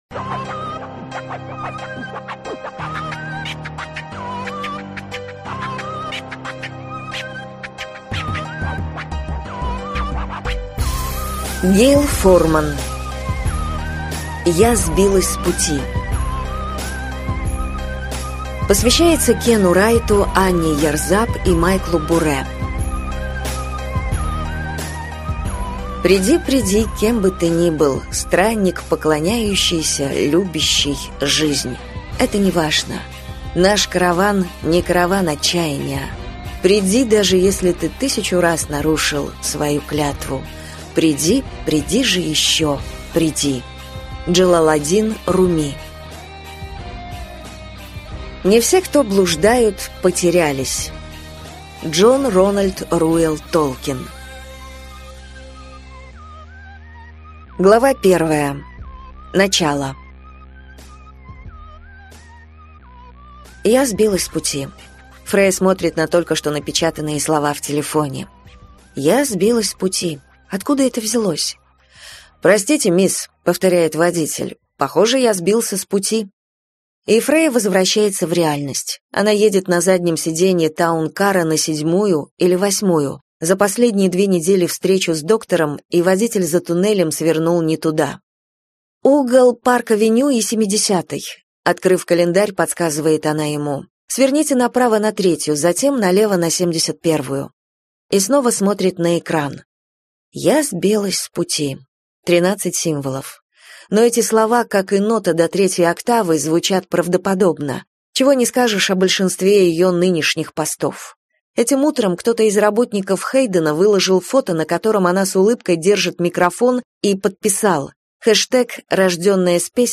Аудиокнига Я сбилась с пути - купить, скачать и слушать онлайн | КнигоПоиск